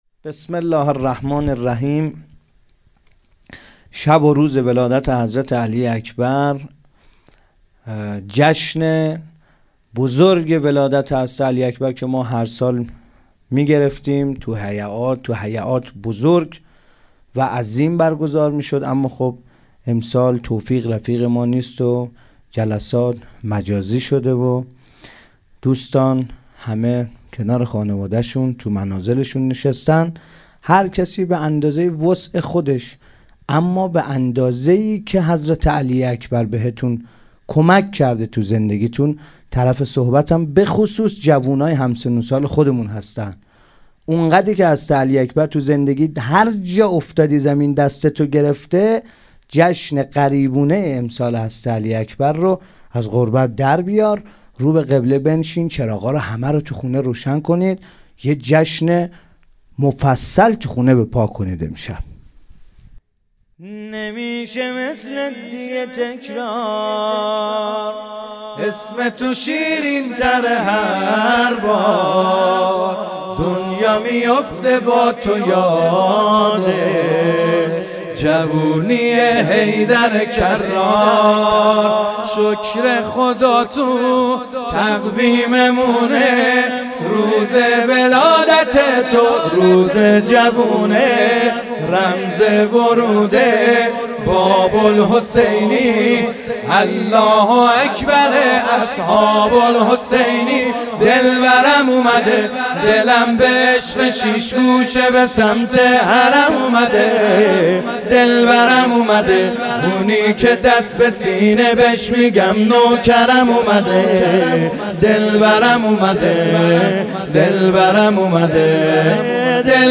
همخوانی طاهری و حدادیان/ اومده آیینه حیدر کرار
محمدحسین حدادیان و حسین طاهری مداحان اهل بیت(ع) در مراسم جشن میلاد حضرت علی اکبر(ع) هیأت فدائیان حضرت زهرا(س) شب گذشته شنبه ۱۶ فروردین‌ماه به دلیل شیوع ویروس کرونا در قالب حسینیه مجازی مدیحه‌سرایی کردند.
در این مراسم محمدحسین حدادیان و حسین طاهری مداحان اهل بیت(ع) سرودی را همخوانی کردند که بخشی از شعر آن به شرح زیر است: